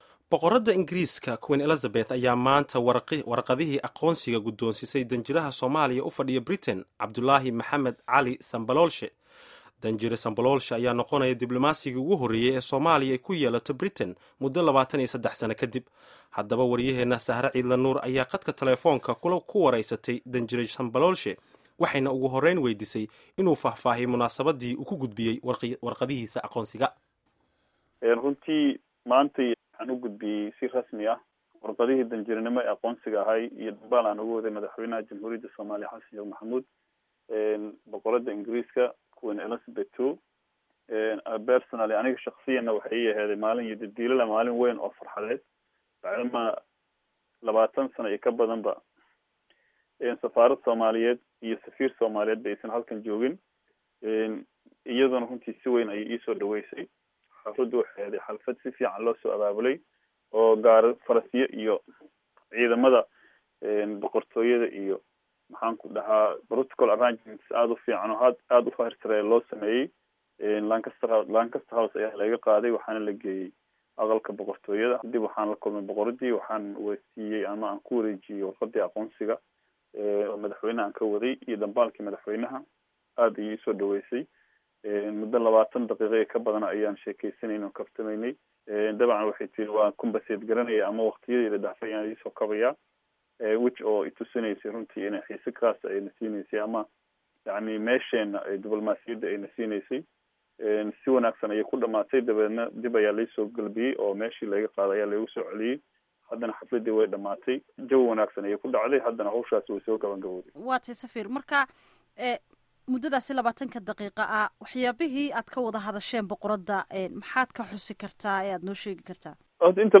Dhageyso Wareysiga Safiirka